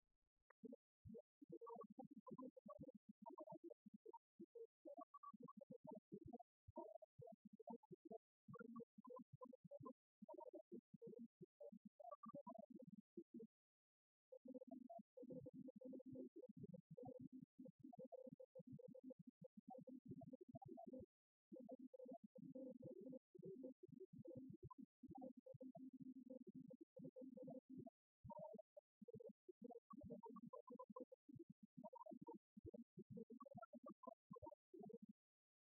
Salut à Beaupreau par l'harmonie de Münsingen
joué pour l'inauguration de la place Müsingen
Cérémonies officielles du jumelage
Pièce musicale inédite